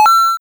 success48.wav